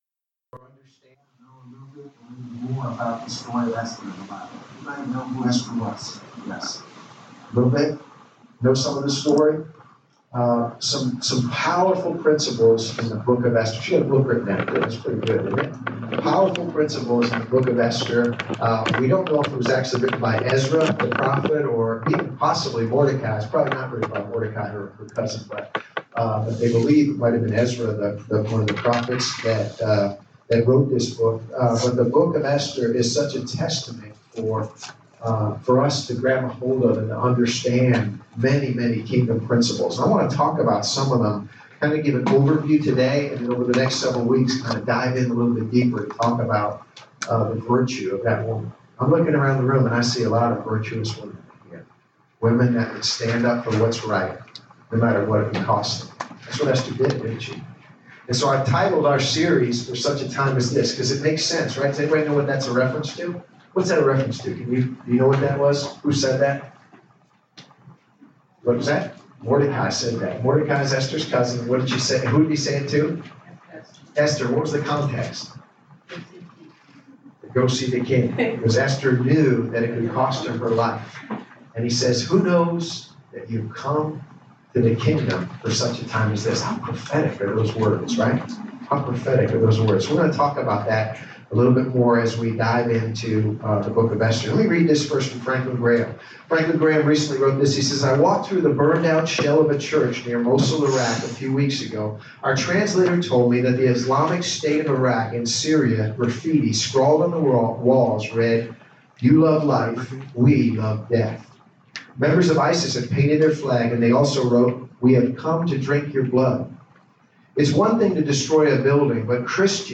Series: Esther Service Type: Sunday Service